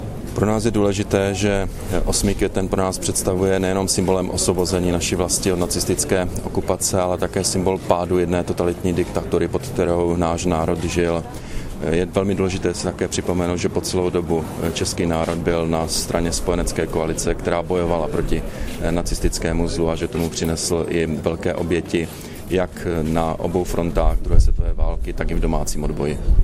Vyjádření premiéra Petra Nečase po skončení pietního aktu na Vítkově